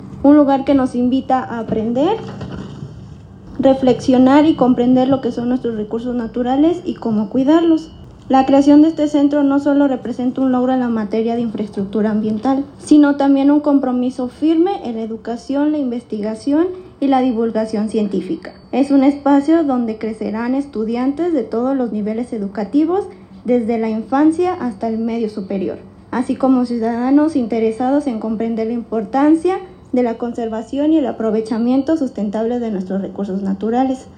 La inauguración del Centro de Educación Ambiental, se dio como parte de los festejos por el 478 aniversario de la fundación de Irapuato.